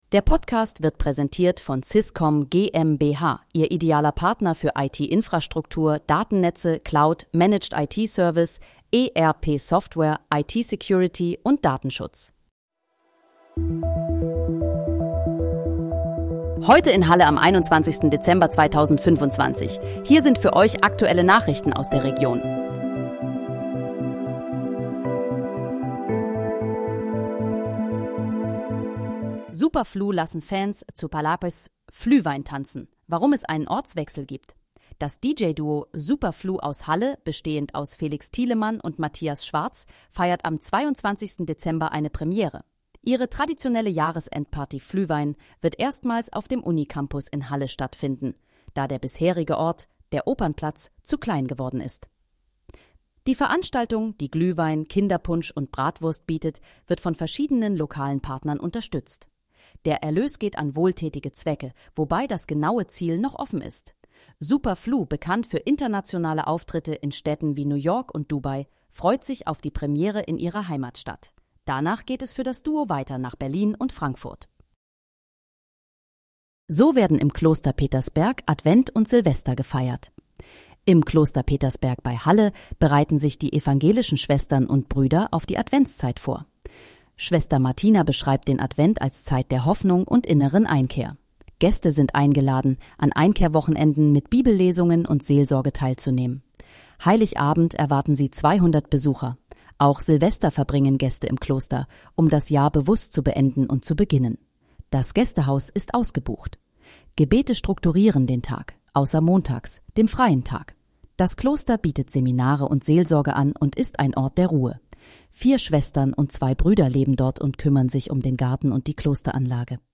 Heute in, Halle: Aktuelle Nachrichten vom 21.12.2025, erstellt mit KI-Unterstützung
Nachrichten